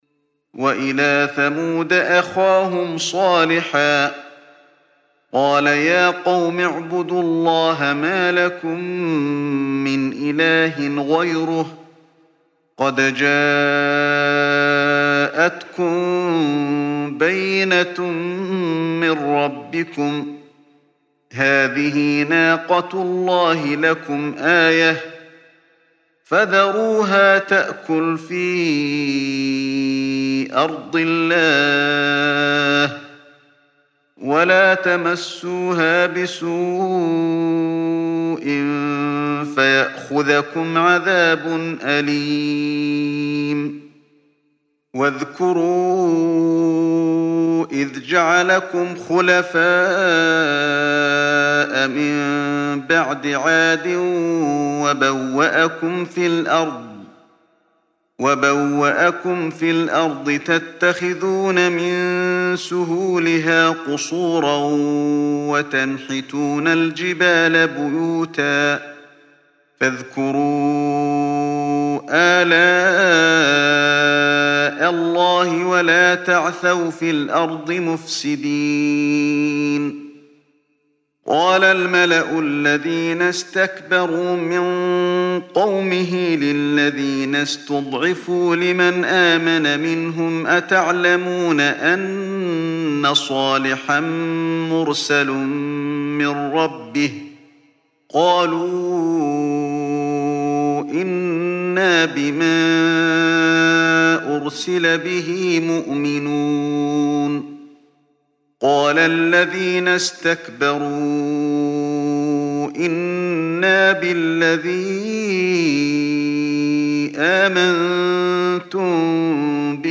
Чтение аятов 73-79 суры «аль-А’раф» шейхом ’Али бин ’Абд ар-Рахманом аль-Хузейфи, да хранит его Аллах.